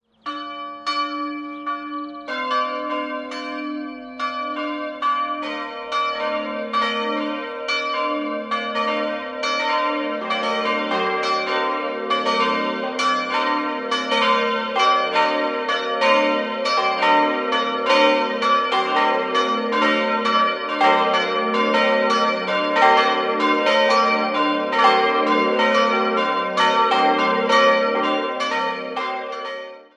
In den Jahren 1923/24 erfolgte die Vergrößerung des Langhauses. 4-stimmiges Geläute: g'-b'-c''-d'' Die kleine Glocke ist historisch, die drei größeren wurden im Jahr 1950 von Karl Czudnochowsky in Erding gegossen.